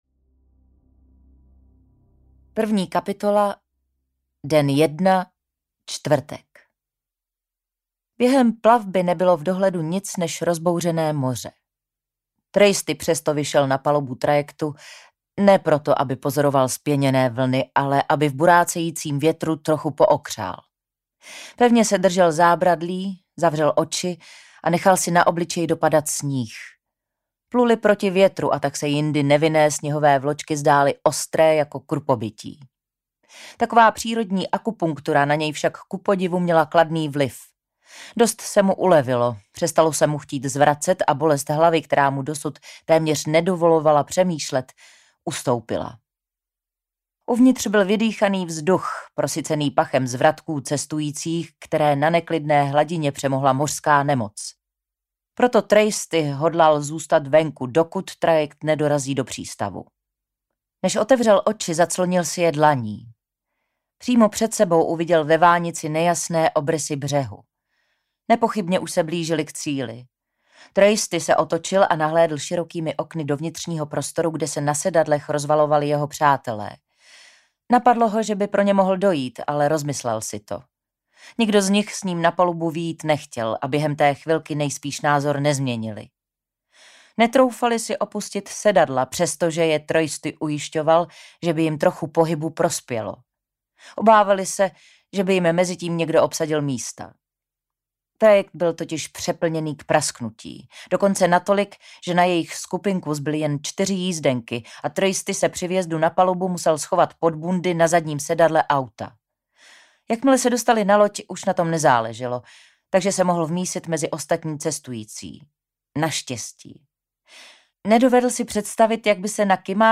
Hrob audiokniha
Ukázka z knihy
• InterpretKlára Cibulková